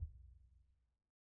BDrumNewhit_v1_rr2_Sum.wav